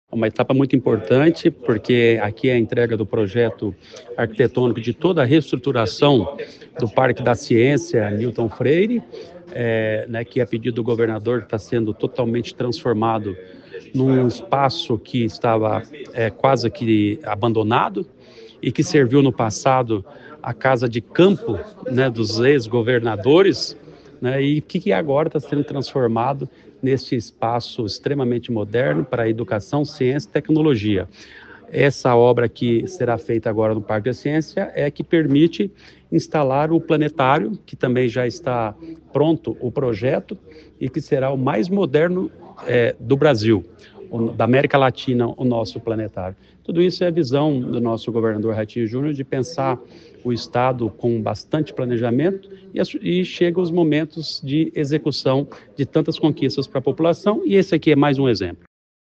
Sonora do secretário do Planejamento, Ulisses Maia, sobre o projeto de revitalização do Parque da Ciência Newton Freire Maia